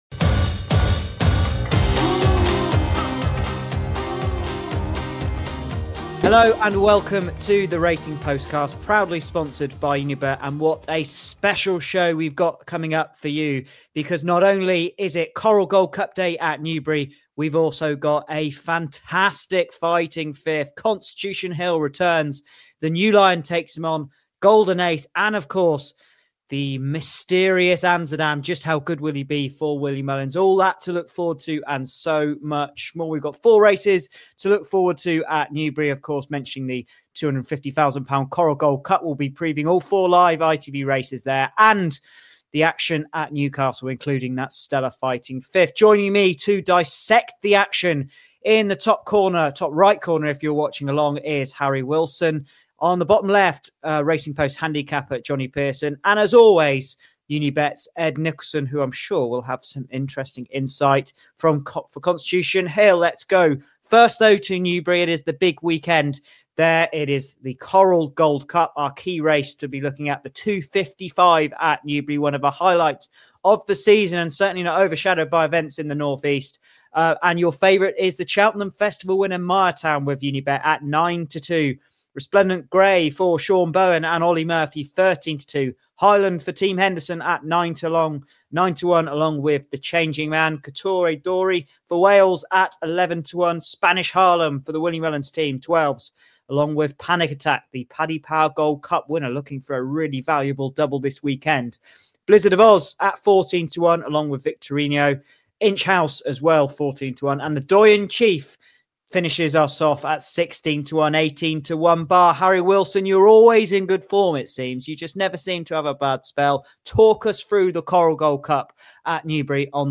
The panel break down a wide-open Coral Gold Cup, assessing the main contenders and highlighting where the value might lie. The team then take aim at Newcastle's Fighting Fifth.